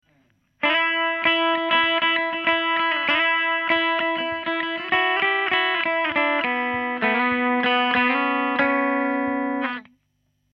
A drone string can also be used to ring out while playing a melody on the adjacent string. This creates interesting harmonies and while again creating a fuller sound in comparison to a single note melody or solo.
Open String E Drone Lick
openstring_edrone.mp3